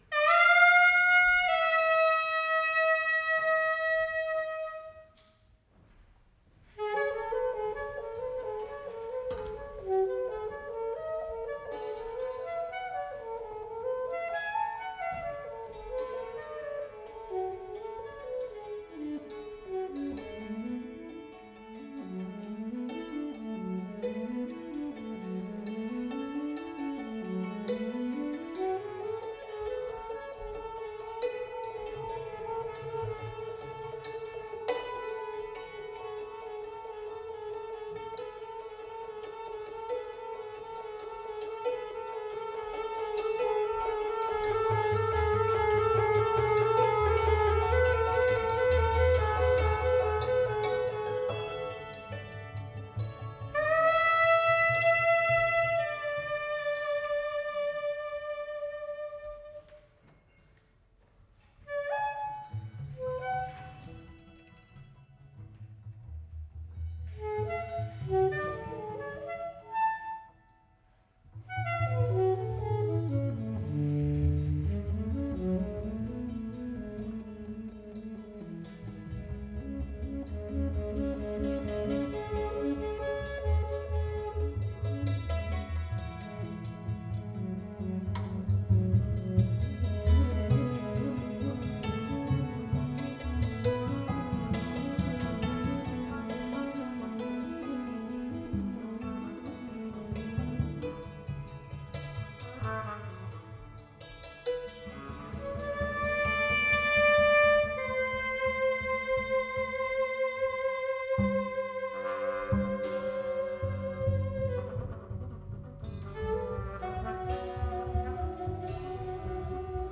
recorded live @ Felix Meritis in Amsterdam